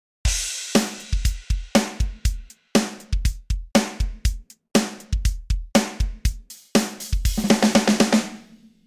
MT Power Drum Kit 2縺ｯ辟｡譁吶〒菴ｿ縺医ｋ繝峨Λ繝髻ｳ貅舌〒縺吶ゅΟ繝繧ｯ蜷代¢縺ｮ繝代Ρ繝輔Ν縺ｪ繝峨Λ繝繧ｵ繧ｦ繝ｳ繝峨′迚ｹ蠕ｴ縺ｧ縲∝晏ｿ閠縺ｧ繧よ桶縺繧縺吶＞縺ｮ縺碁ｭ蜉帙〒縺吶
MT Power Drum Kit 2縺ｮ繧ｵ繝ｳ繝励Ν髻ｳ貅